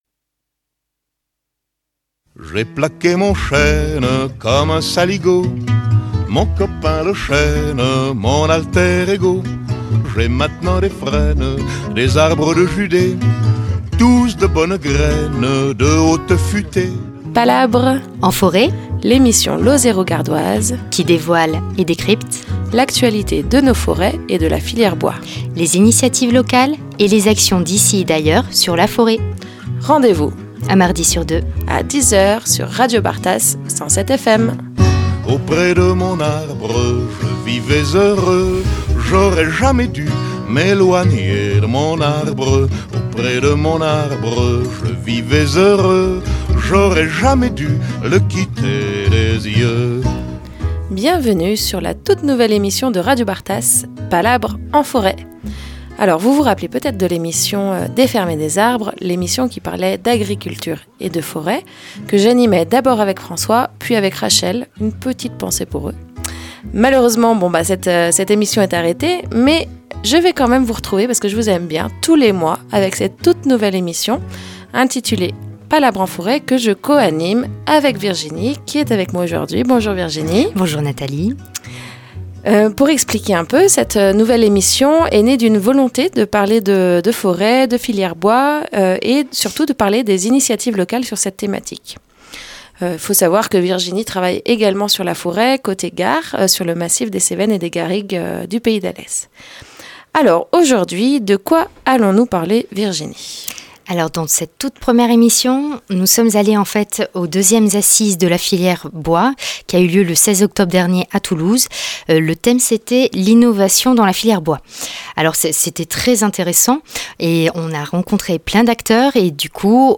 ont été interroger des acteurs suite aux 2èmes assises de la filière bois